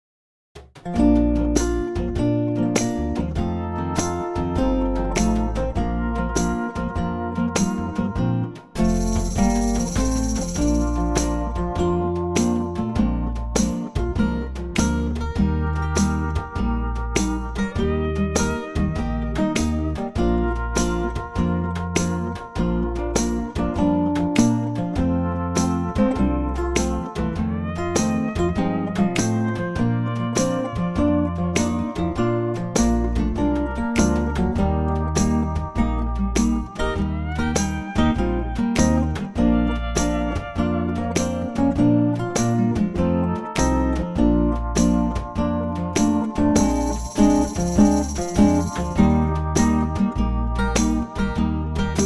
Unique Backing Tracks
key - Bb - vocal range - F to Bb
A superb acoustic arrangement